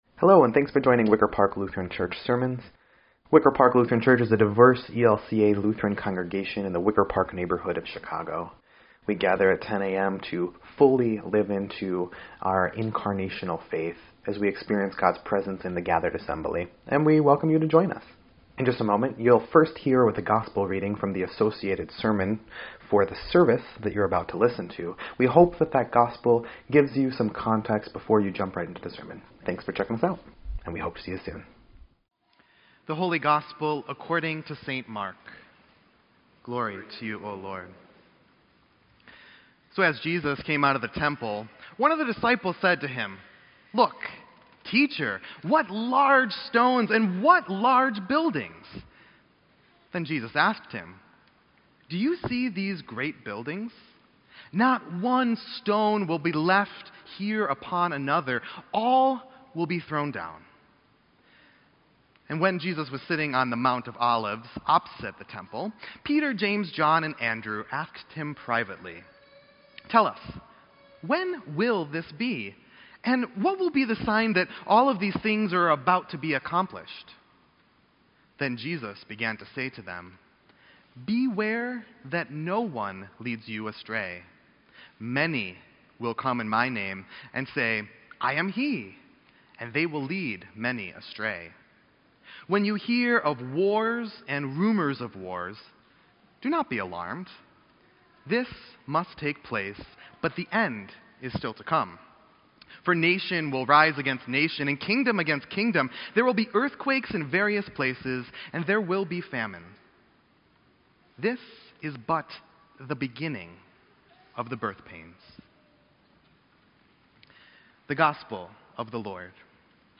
Sermon_11_18_18.mp3